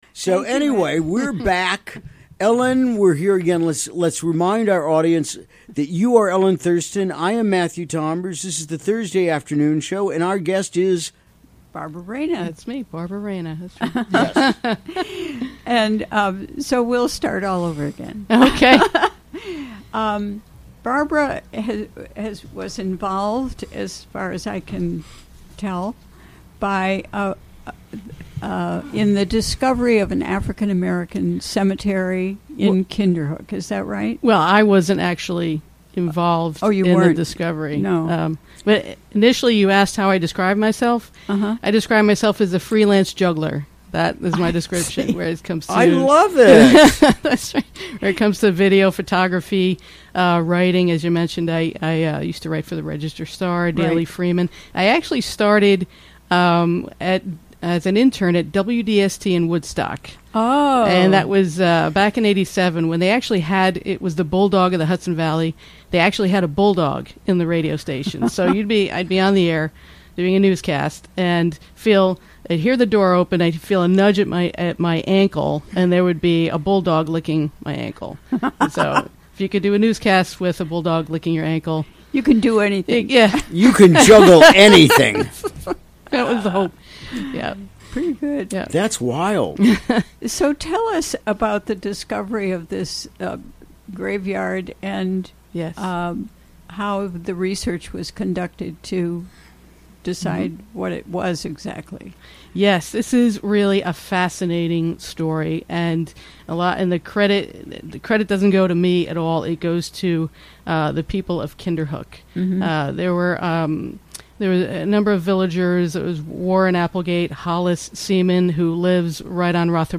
Recorded during the WGXC Afternoon Show on Thursday, November 2, 2017.